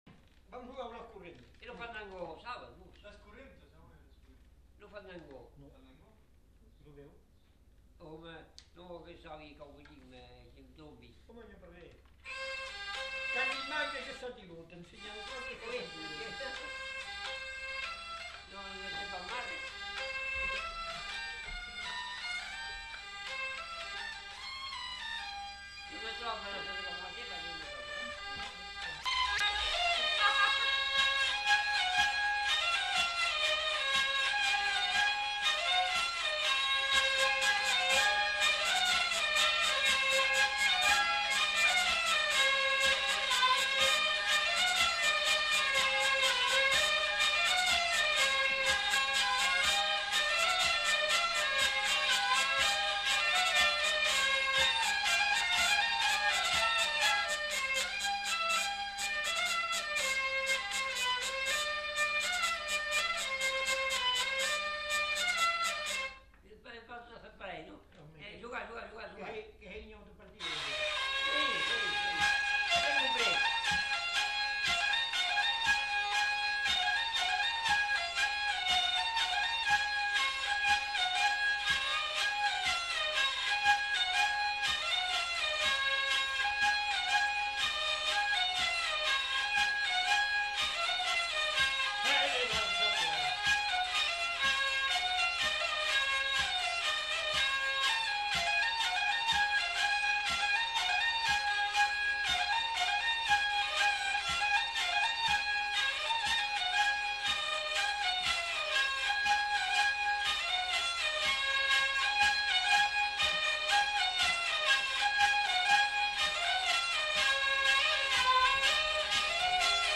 Aire culturelle : Gabardan
Lieu : Vielle-Soubiran
Genre : morceau instrumental
Instrument de musique : vielle à roue
Danse : courante
Notes consultables : Enchaînement de plusieurs thèmes.